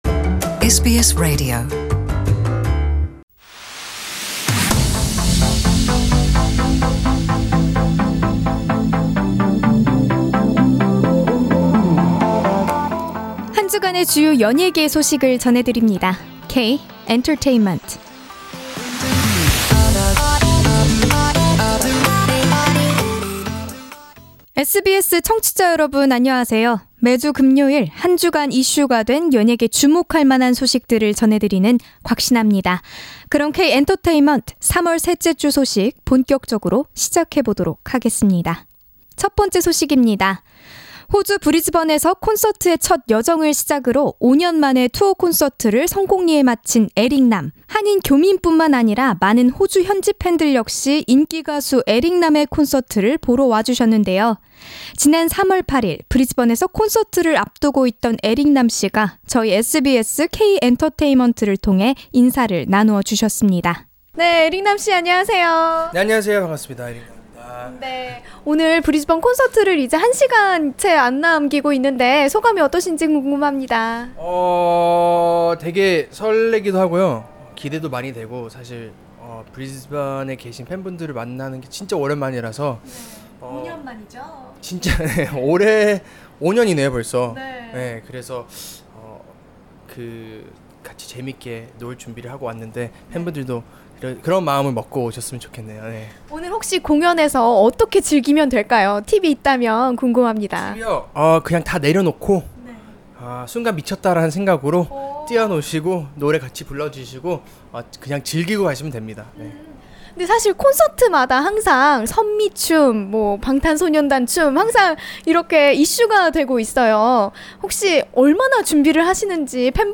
Before his concert in Brisbane, Eric Nam has spoken to SBS K- Entertainment to talk about his excitement abouth the Australian tour.